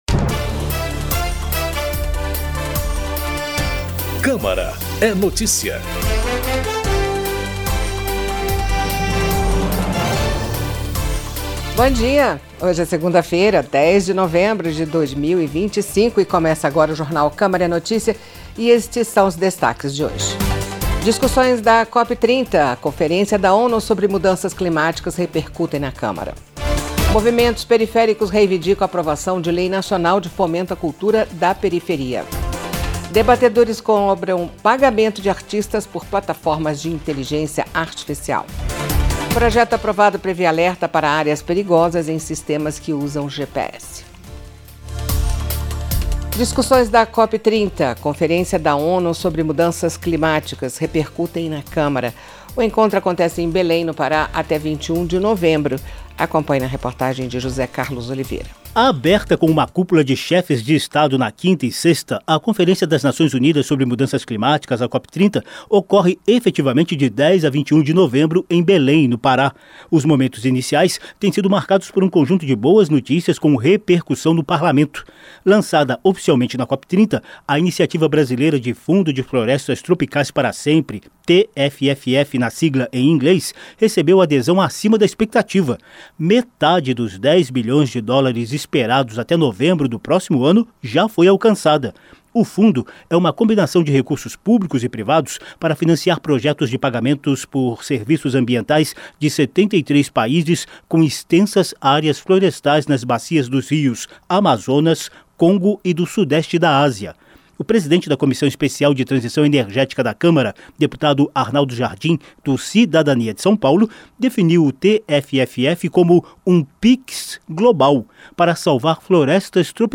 Jornal com as últimas notícias da Câmara dos Deputados